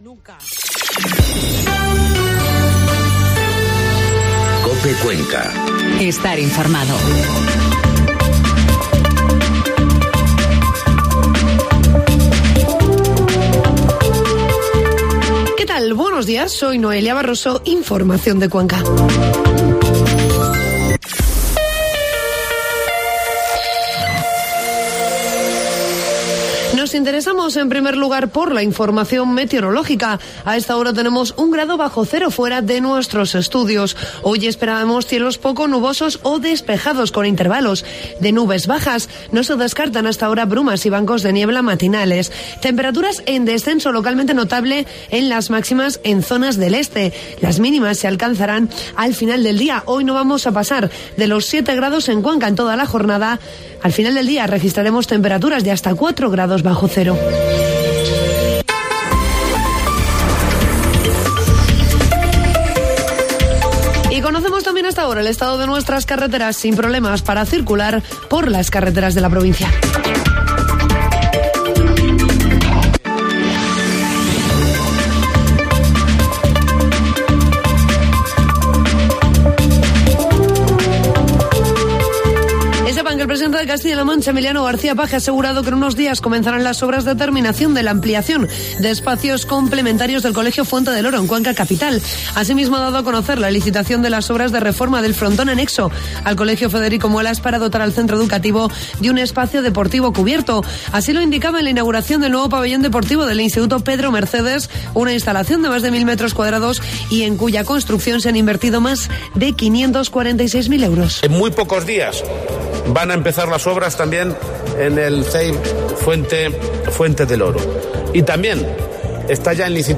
Informativo matinal COPE Cuenca 10 de enero